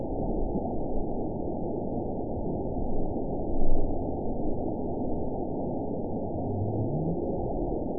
event 912489 date 03/27/22 time 16:09:41 GMT (3 years, 1 month ago) score 9.40 location TSS-AB01 detected by nrw target species NRW annotations +NRW Spectrogram: Frequency (kHz) vs. Time (s) audio not available .wav